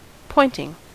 Ääntäminen
Ääntäminen US : IPA : [ˈpɔɪn.tɪŋ] Haettu sana löytyi näillä lähdekielillä: englanti Käännöksiä ei löytynyt valitulle kohdekielelle.